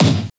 Index of /90_sSampleCDs/Roland L-CDX-01/KIT_Drum Kits 6/KIT_Video Kit
KIK VIDEO K1.wav